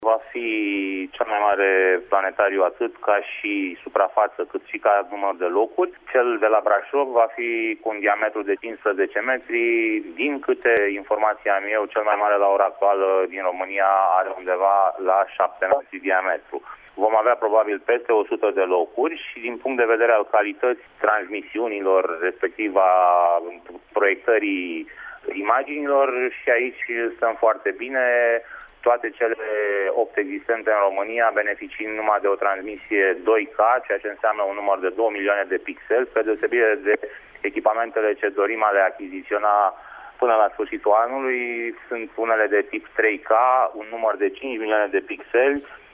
Acesta va fi găzduit de Grădina Zoologică și va avea un diametru de 15 metri, a explicat pentru Radio Tîrgu-Mureș, viceprimarul municipiului Brașov, Ciprian Bucur: